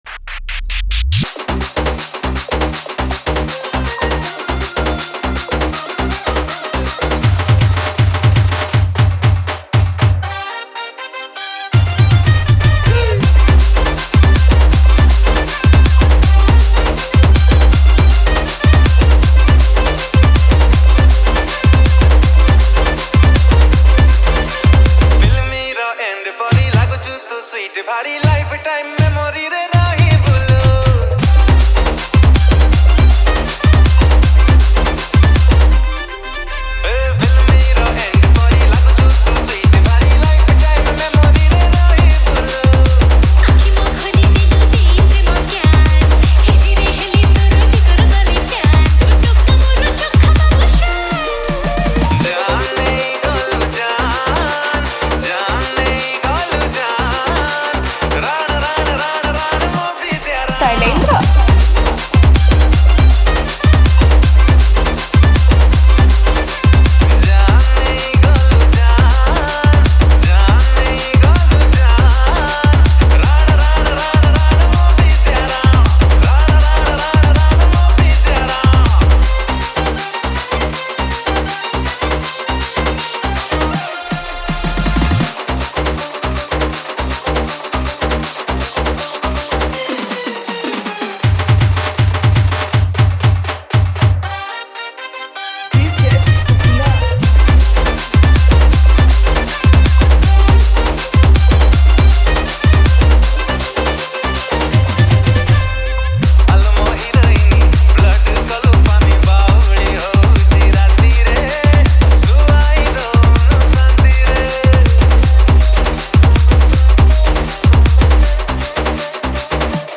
Category:  New Odia Dj Song 2022